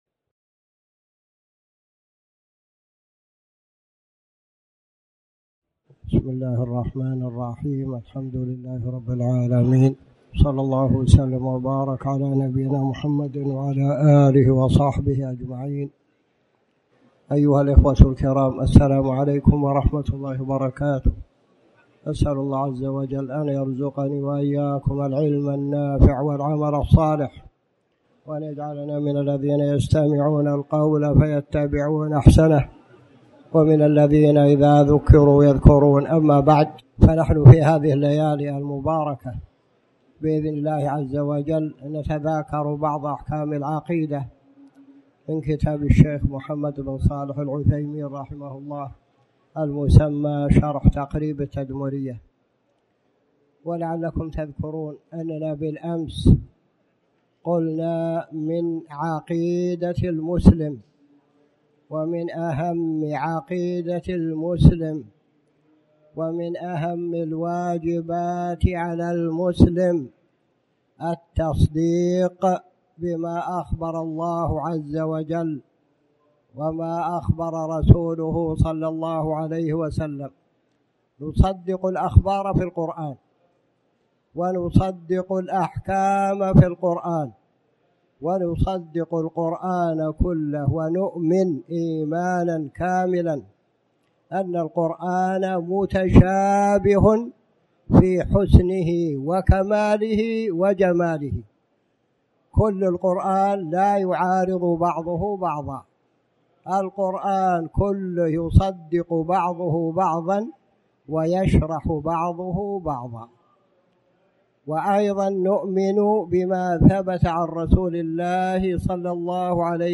تاريخ النشر ٢٣ ذو القعدة ١٤٣٩ هـ المكان: المسجد الحرام الشيخ